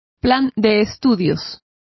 Complete with pronunciation of the translation of syllabuses.